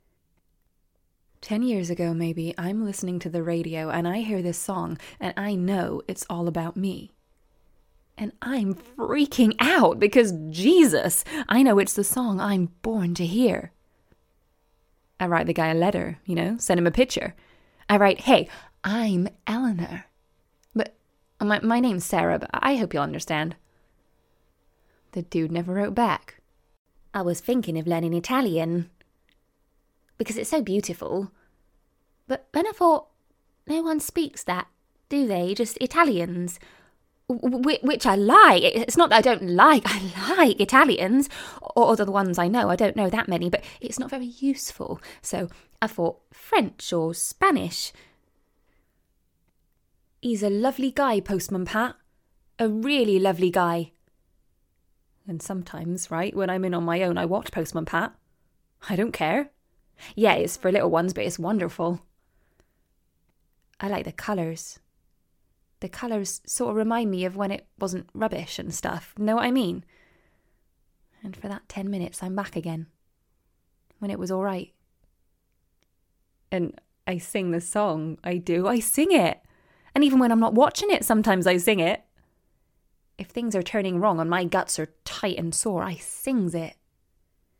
• Native Accent: Bristol, RP, Somerset, West Country
• Home Studio